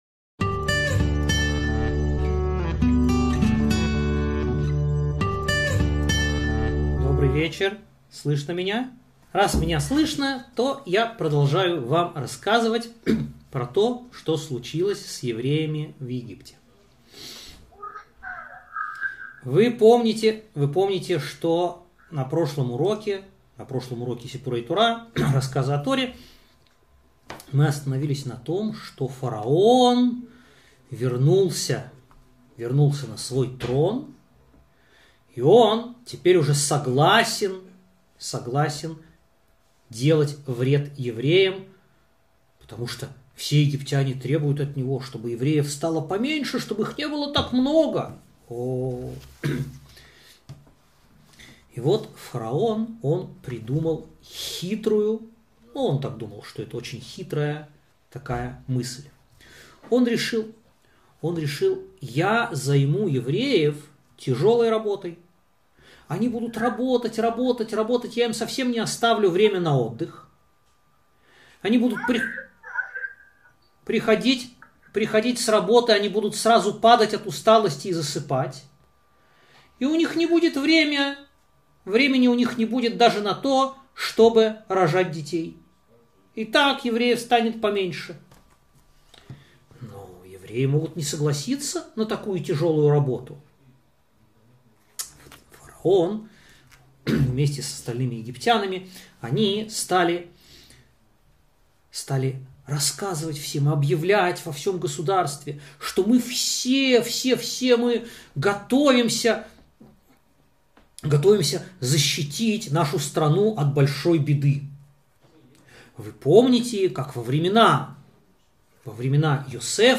Замысел фараона - 57 урок